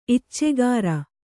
♪ iccegāra